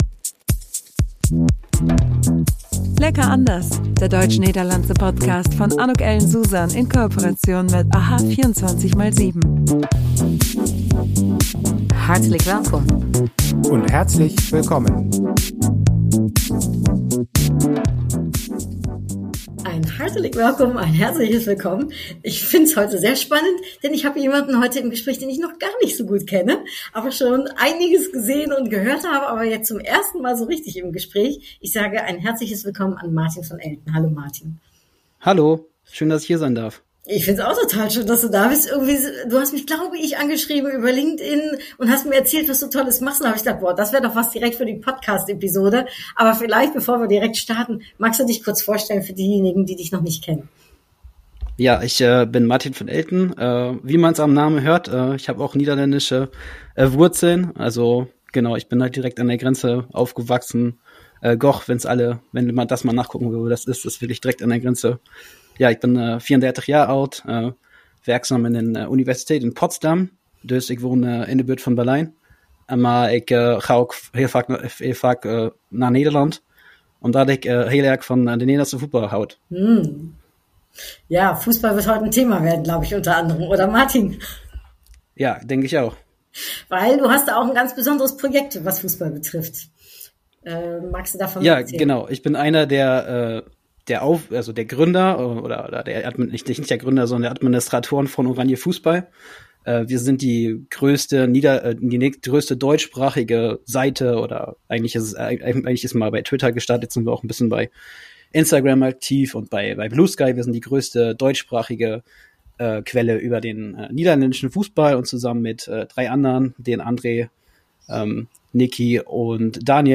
Ein richtig cooles tiefgehendes Fussball-Gespräch, alles dreht sich rund um den Ball!!!